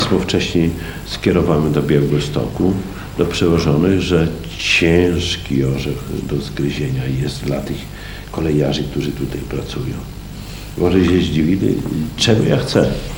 O szczegółach mówił na niedawnej konferencji prasowej Czesław Renkiewicz, prezydent Suwałk.